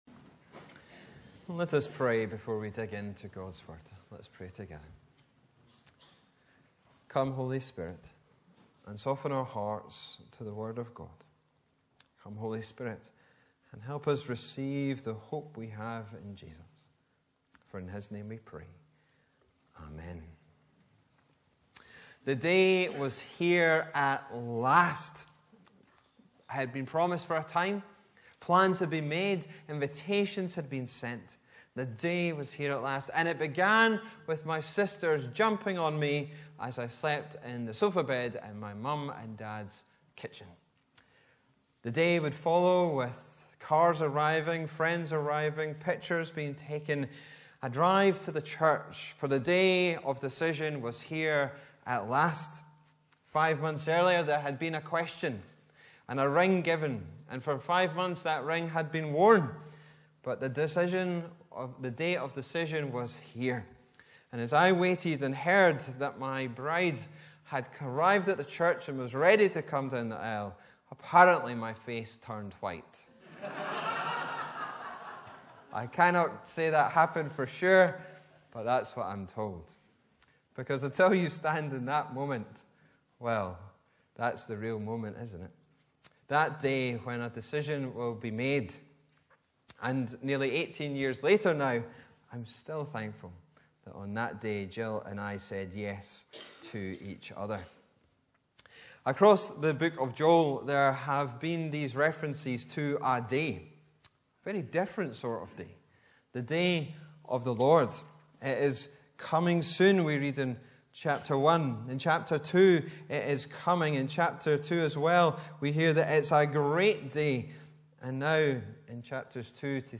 May 21, 2023 Day of decision MP3 Subscribe to podcast Notes Sermons in this Series Preached on: Sunday 21st May 2023 The sermon text is available as subtitles in the Youtube video (the accuracy of which is not guaranteed).
Bible references: Joel 2:2:30 - 3:21 Location: Brightons Parish Church Show sermon text Sermon keypoints: - There is a final Day of the Lord - By Jesus we are saved - In these last days, live by the Spirit Day of decision Rend your heart Hardships Lament